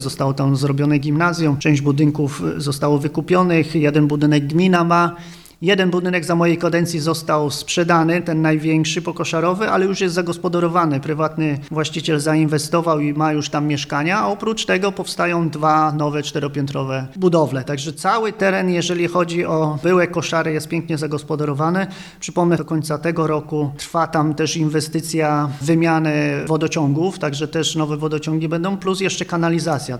– Mieliśmy szczęście do inwestorów i powstało tam piękne osiedle – powiedział burmistrz Paweł Jagasek: